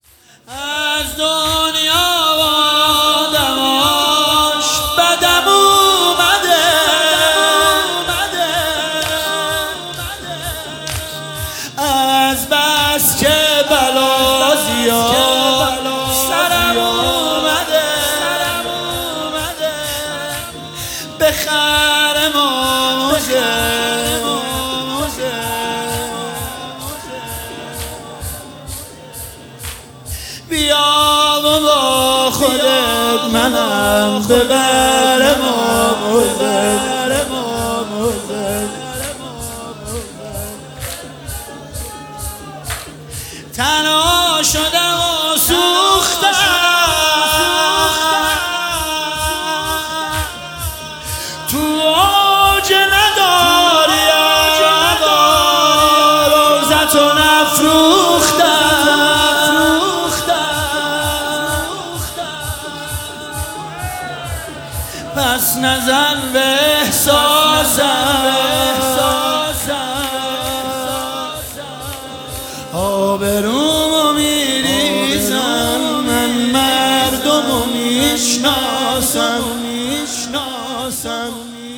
مداحی شور
شبهای قدر
شب 21 ماه رمضان 1446